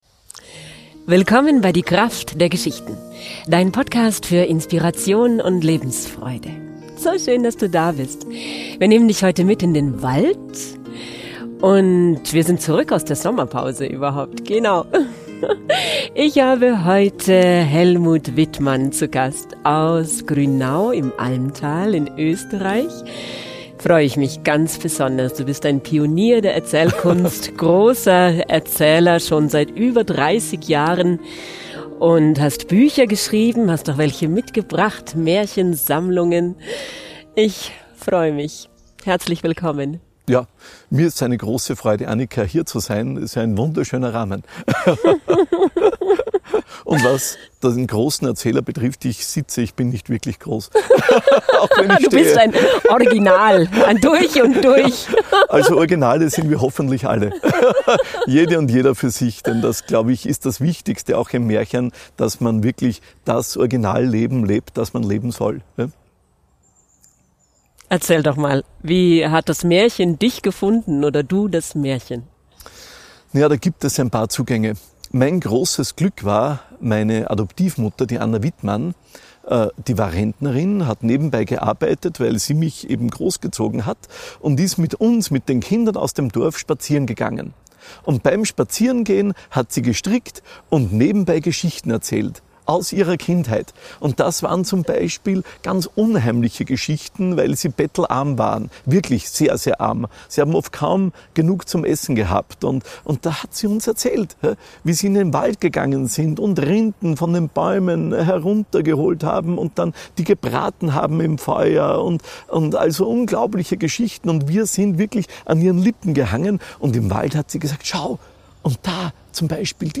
Mit einem ganz besonderen Interview-Gast.